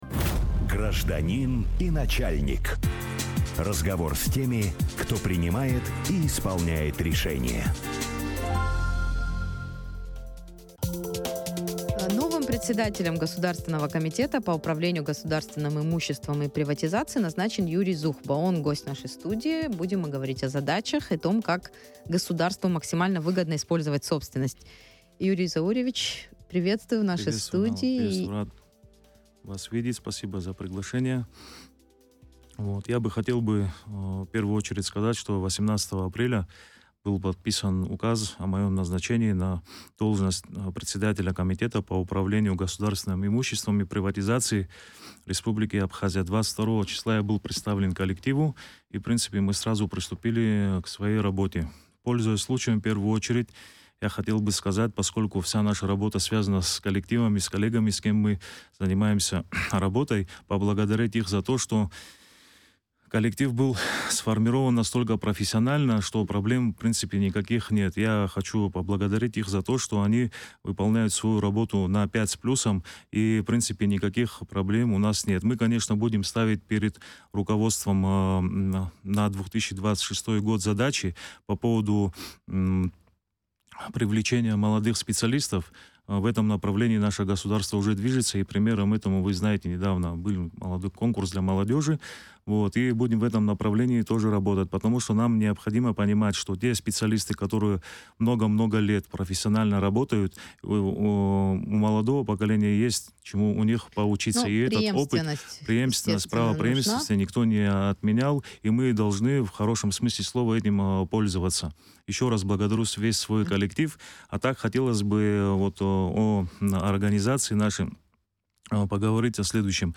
Председателем Госкомимущества и приватизации назначен Юрий Зухба. О задачах, которые предстоит решать на этом посту, о том, как государству максимально выгодно использовать собственность, Зухба рассказал в эфире радио Sputnik.